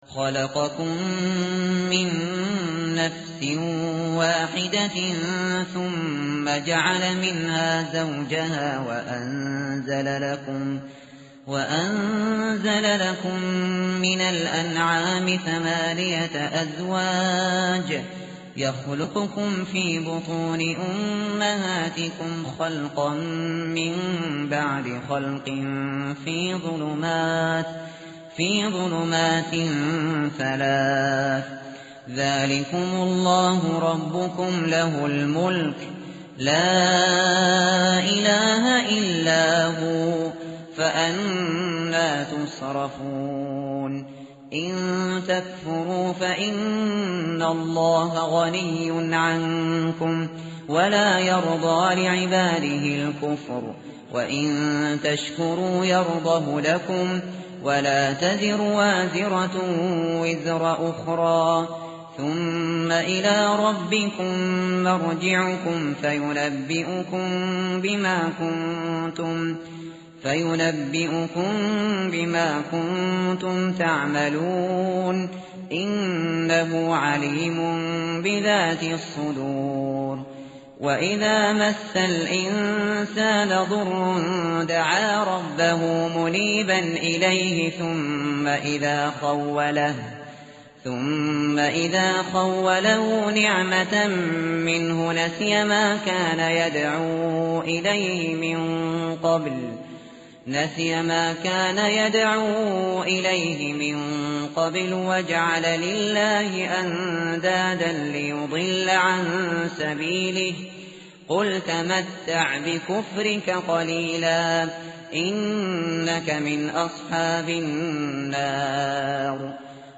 متن قرآن همراه باتلاوت قرآن و ترجمه
tartil_shateri_page_459.mp3